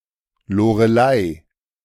The Lorelei (/ˈlɒrəl/ LORR-ə-ly; German: Loreley or Lorelei, pronounced [loːʁəˈlaɪ̯]
De-Loreley.ogg.mp3